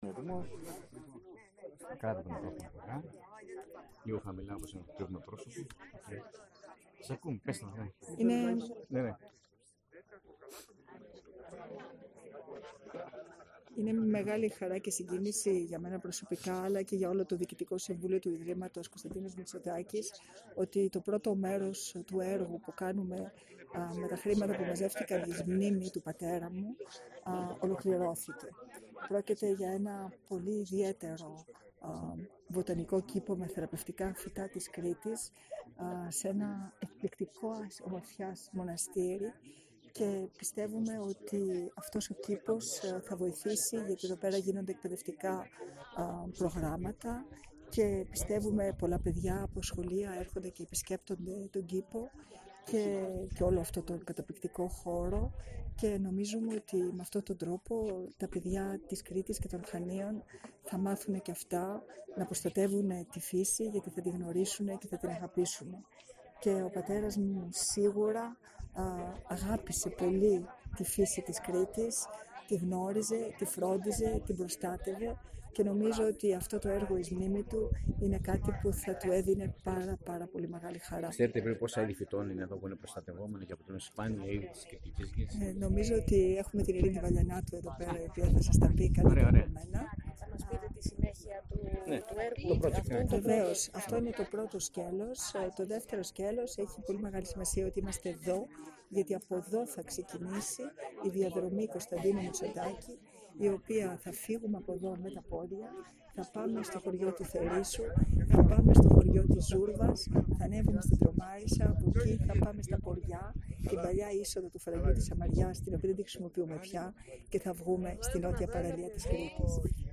Εγκαινιάστηκε το απόγευμα της Κυριακής στο μοναστήρι της Αγίας Κυριακής, στο Βαρύπετρο Χανίων, ο Βοτανικός Κήπος, που σχεδίασε και υλοποίησε το Ίδρυμα Κωνσταντίνος Μητσοτάκης σε συνεργασία με την Ελληνική Εταιρεία προστασίας της φύσης.
Ρεπορτάζ